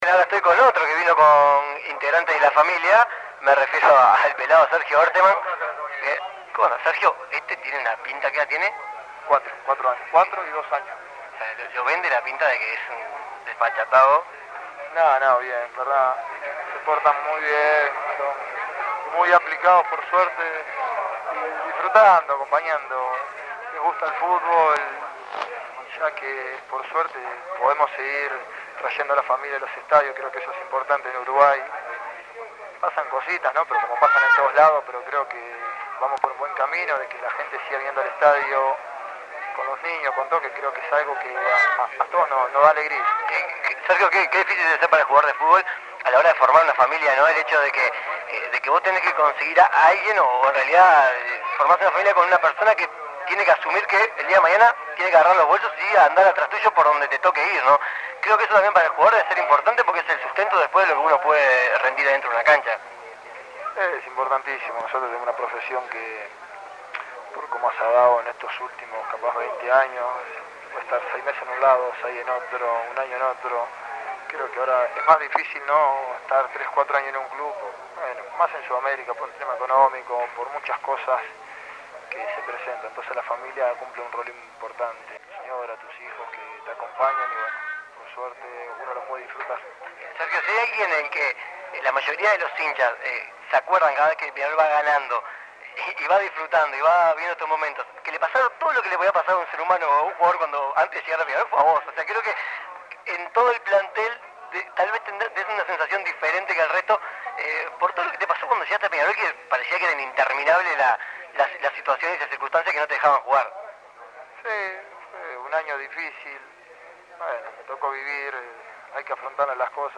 El volante aurinegro habló con 13 a 0 despues de ganar el Clausura. El título, los festejos, sus complicaciones al llegar a Peñarol, las "mascotitas" y la familia en las canchas fueron algunos de los temas que contó el jugador.